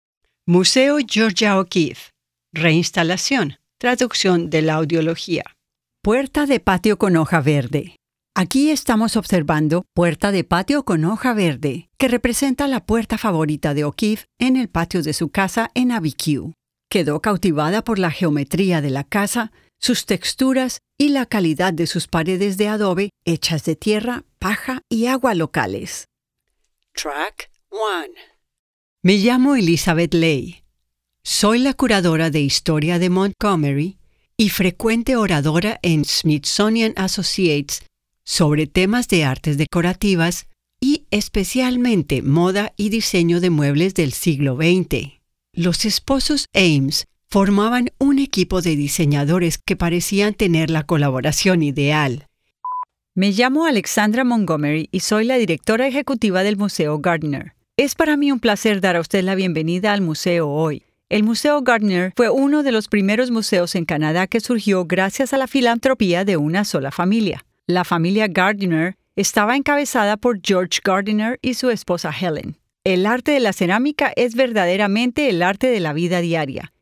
Female
Adult (30-50), Older Sound (50+)
Accent: Spanish-Colombian, Latin American Neutral.
Voice: mature, caring, warm, fun, theatrical, energetic. or sexy.
Main Demo